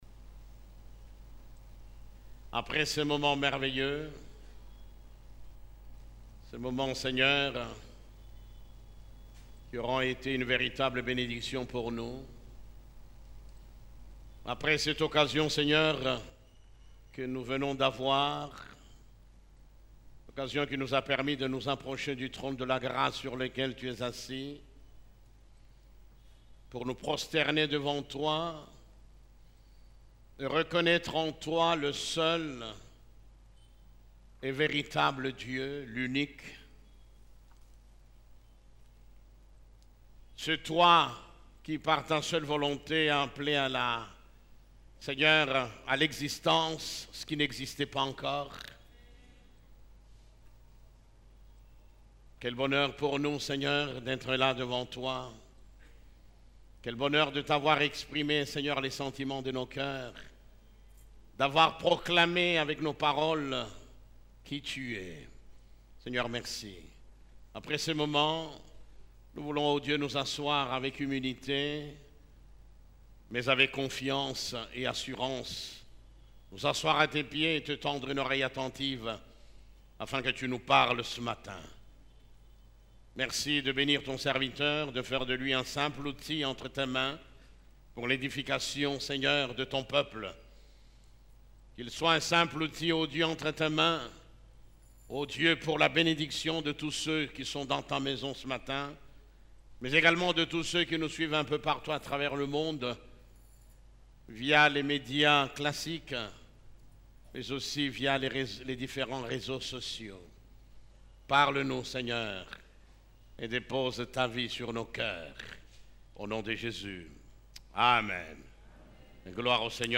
Culte du Dimanche